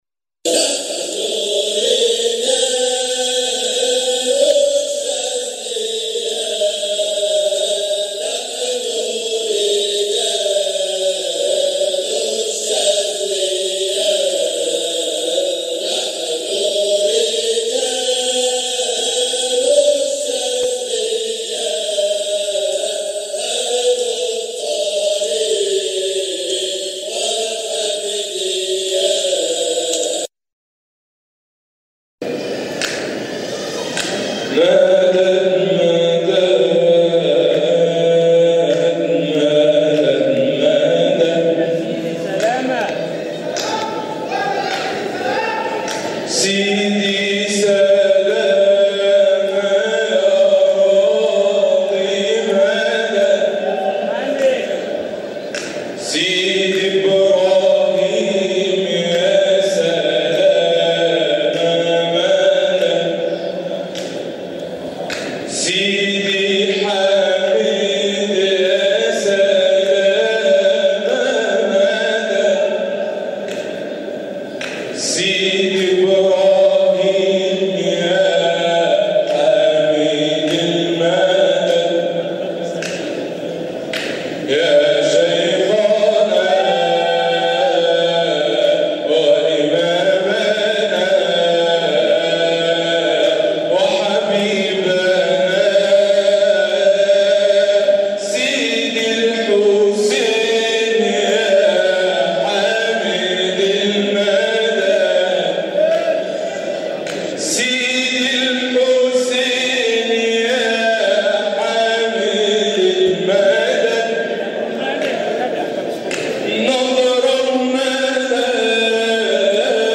جزء من حلقة ذكر بمسجد سيدنا ابراهيم الدسوقي قدس سره 2018